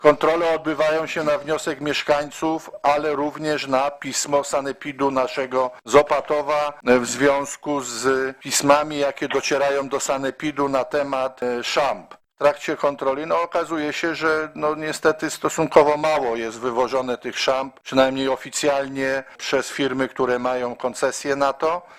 Mówi wiceburmistrz Paweł Rędziak: